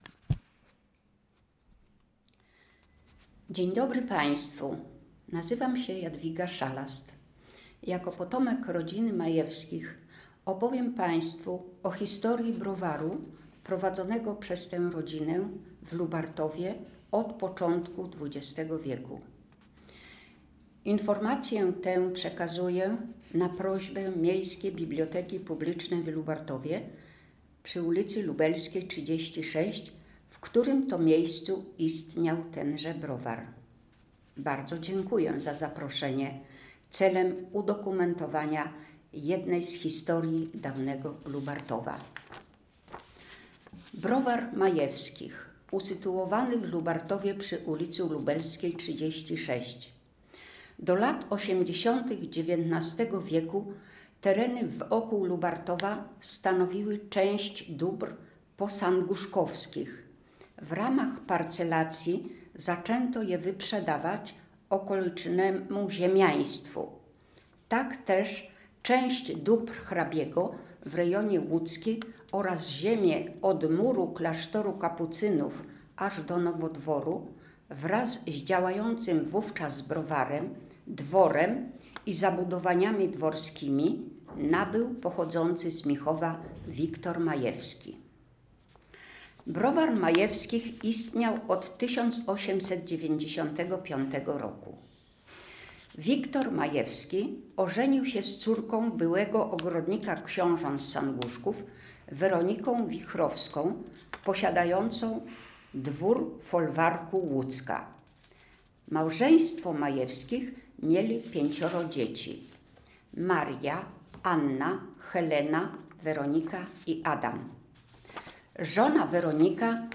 Nagranie audio zostało zarejestrowane podczas kolejnego spotkania w ramach cyklu "Cyfrowa Biblioteka Pamięci" w dniu 24.04.2023 r. Są to spotkania osób zainteresowanych historią Lubartowa, podczas których dzielą się one swoimi wspomnieniami i materiałami dotyczącymi dawnego życia w naszym mieście.